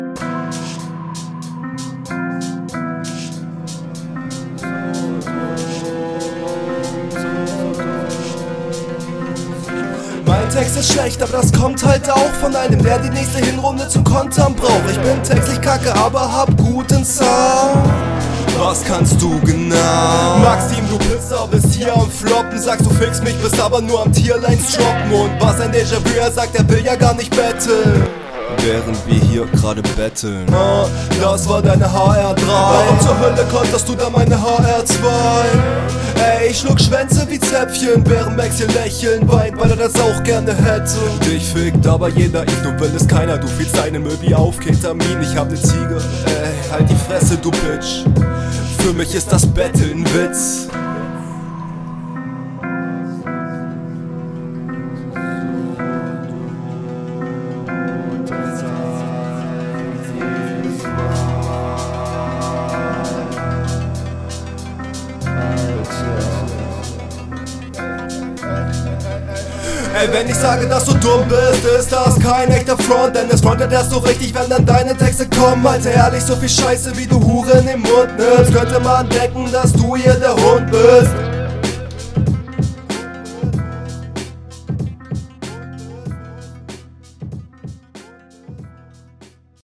Ich mag das Autotune im Intro und am Ende des Parts klingt cool.